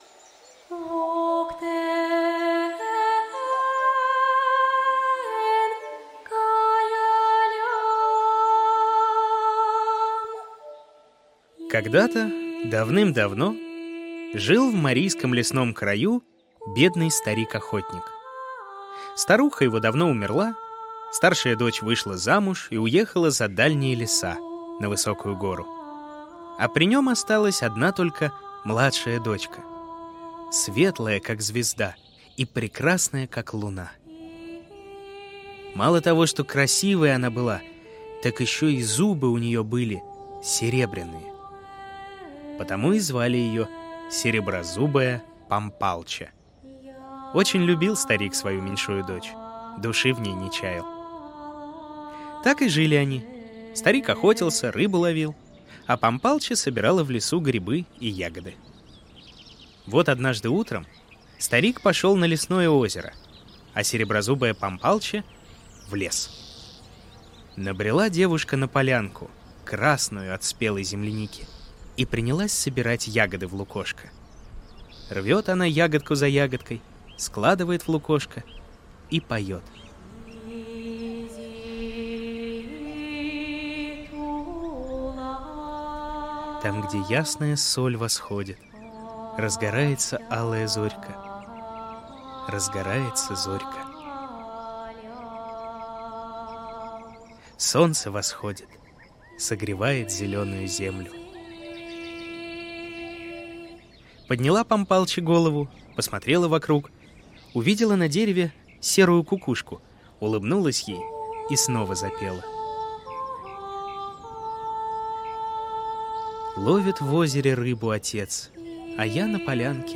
Сереброзубая Пампалче - марийская аудиосказка - слушать